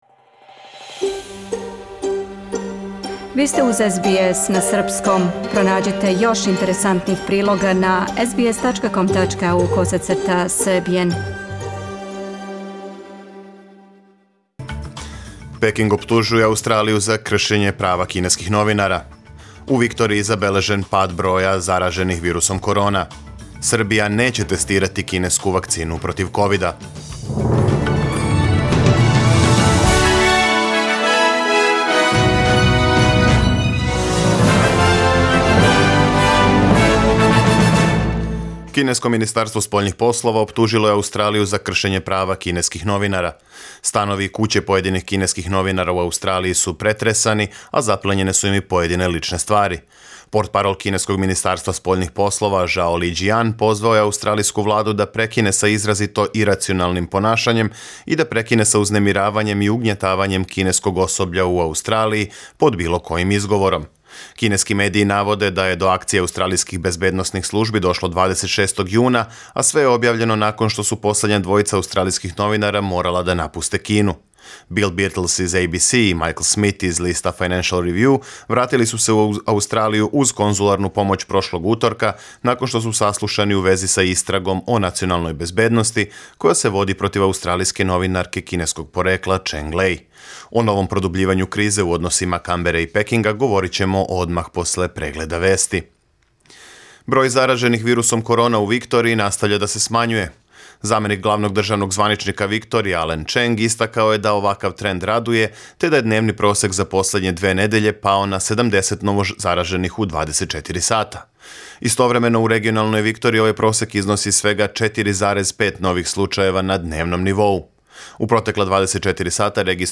Преглед вести за 10. септембар 2020. године